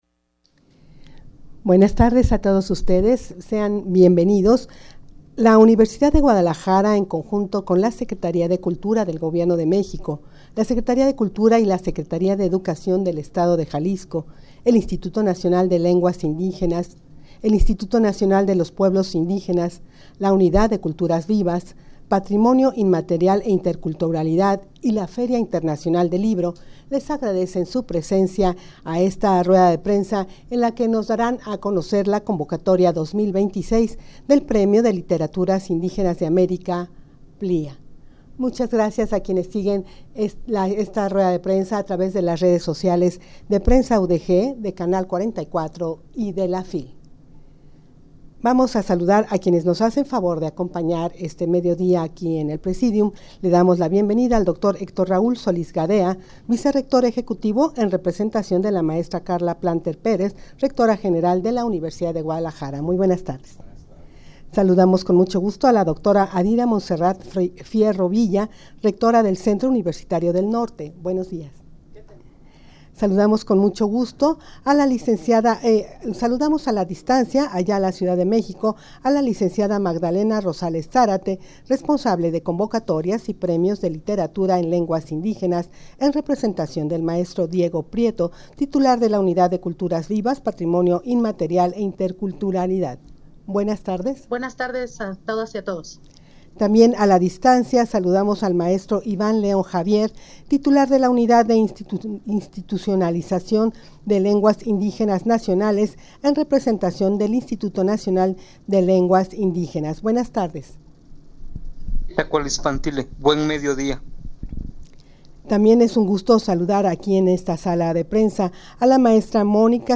Audio de la Rueda de Prensa
rueda-de-prensa-convocatoria-2026-del-premio-de-literaturas-indigenas-de-america-plia_0.mp3